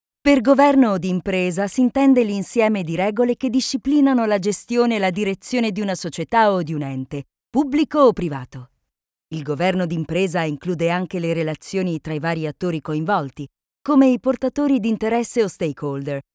Voz cálida masculina tono medio grave excelente dicción
Sprechprobe: eLearning (Muttersprache):